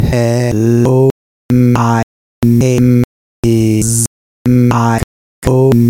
Speech Synth
This was a quick speech synthesis experiment.
For the second part, a test program was made that can take a series of .wav files, trim the empty space from the start / end, normalize it to the loudest volume, and append into a single output file.
hello.mp3